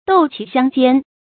豆萁相煎 注音： ㄉㄡˋ ㄑㄧˊ ㄒㄧㄤ ㄐㄧㄢ 讀音讀法： 意思解釋： 同「豆萁燃豆」。